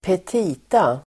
Ladda ner uttalet
Folkets service: petita petita substantiv, (budget) appropriation request Uttal: [²pet'i:ta] Böjningar: petitan, petitor Definition: statliga myndigheters önskemål om anslag för nytt budgetår, anslagsframställning